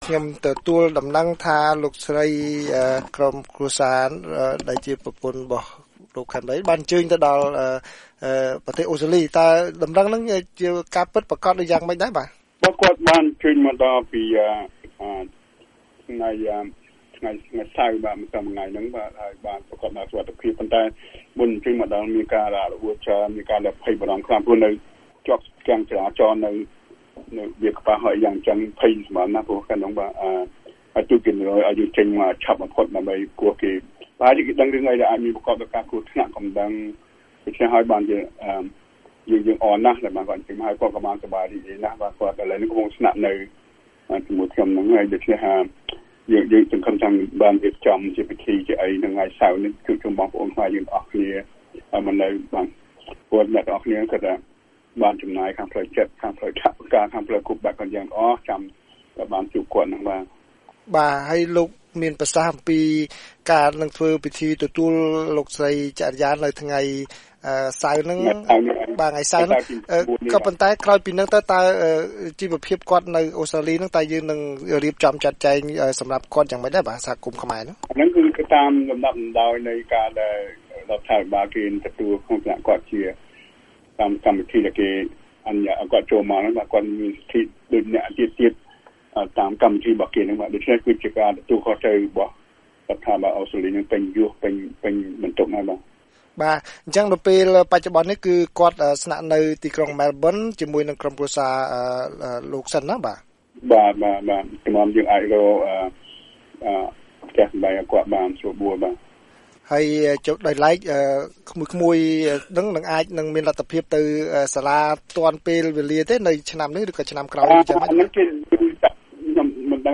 បទសម្ភាសន៍ VOA៖ ក្រុមគ្រួសារលោកកែម ឡីទទួលបានសិទ្ធិជ្រកកោននៅប្រទេសអូស្ត្រាលី